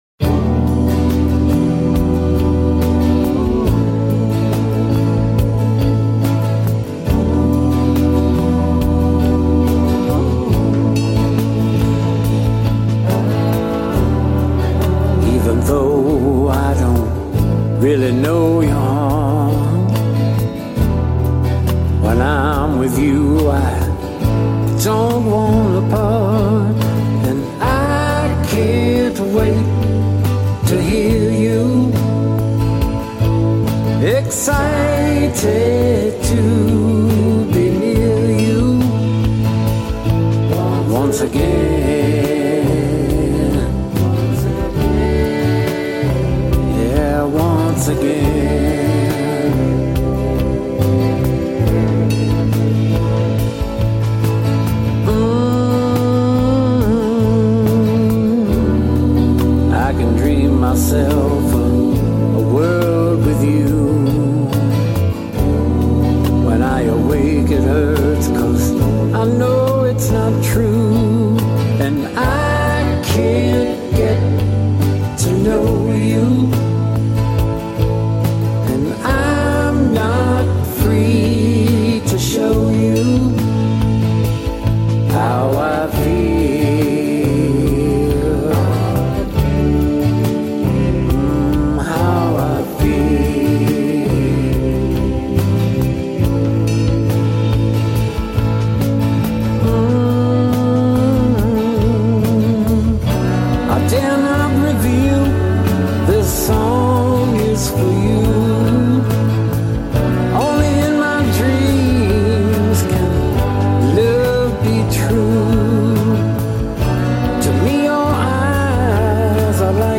Boost the midrange centred at 500hz. finally, boost 12-20khz slightly with a multi band (not in mid/side mode).
Nice work of a super chill tune.